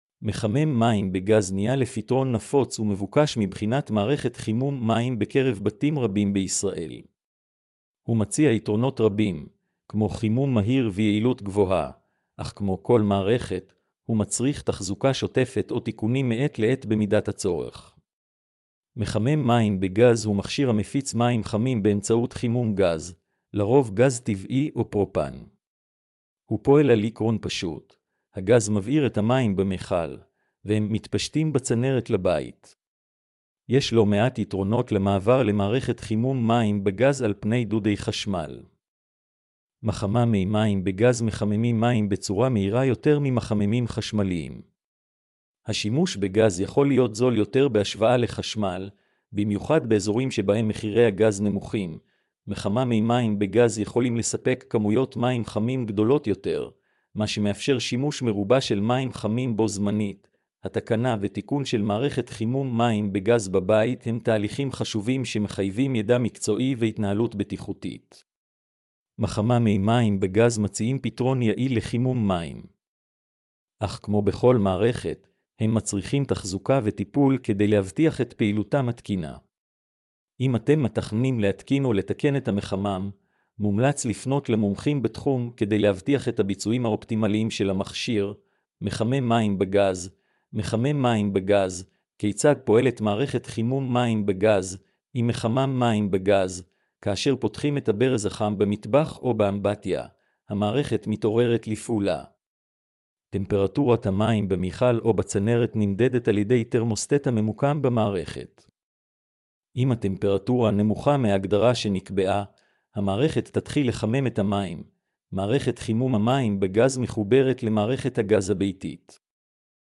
mp3-output-ttsfreedotcom.mp3